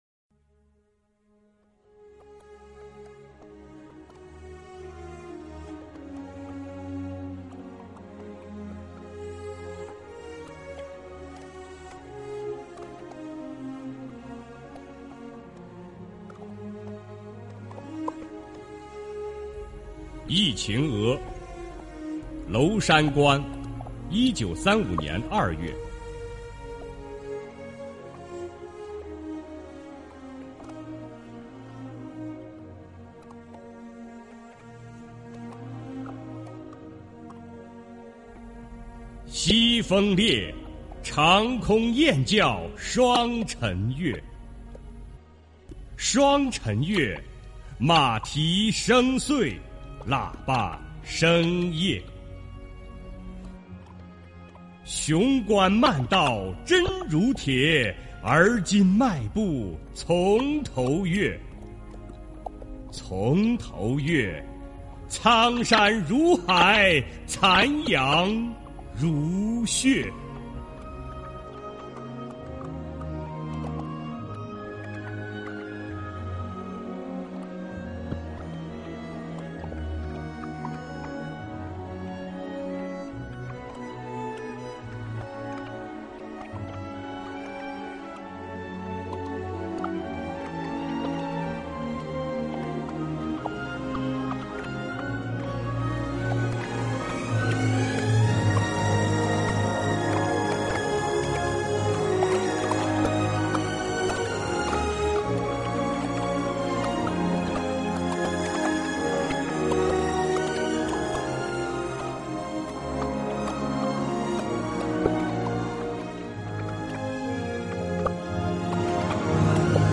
首页 视听 经典朗诵欣赏 毛泽东：崇高优美、超越奇美、豪华精美、风格绝殊